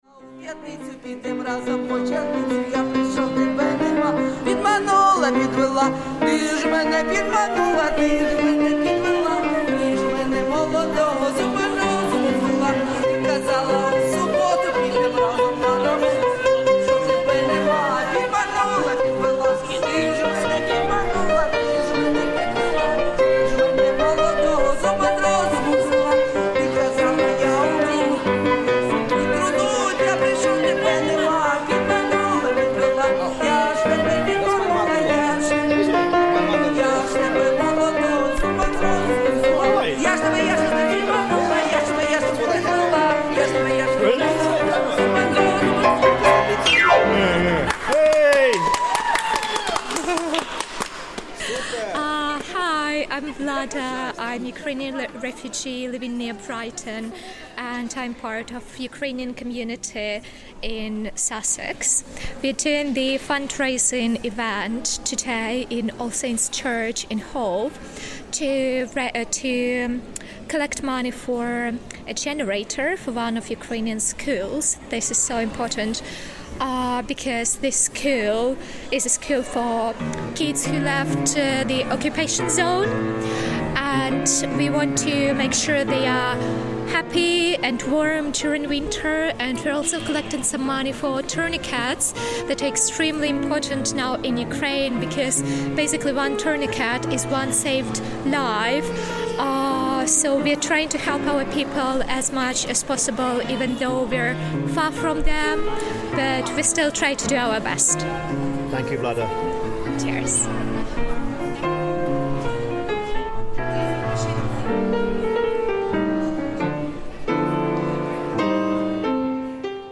Conversations with local Ukrainian people and soundscape of incidental music, and ambience at the Made For Ukraine fundraiser event in All Saints Church, Hove, 21st October 2023.
Music in this recording is not from live music performances on the day, but instead includes clips from song and dance workshops and someone improvising on the piano while the gig was being set up.